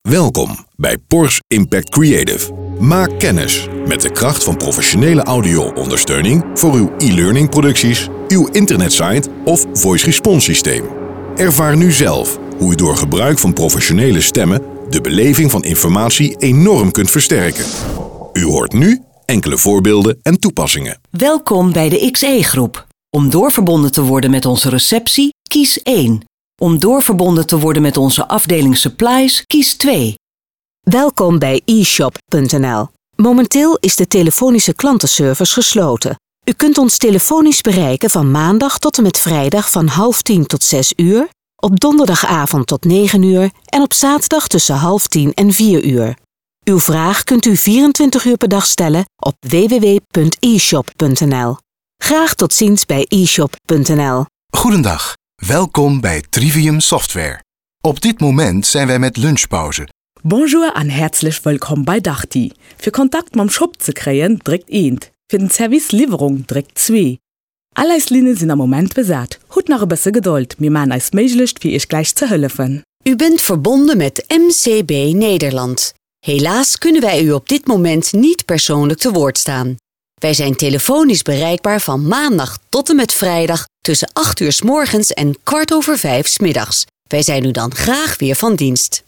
Voice-over
Met mijn warme, lage stem ben ik al meer dan 40 jaar de stem achter talloze radiocommercials, promo’s en jingles.
Stem demo's
DemoVoice.mp3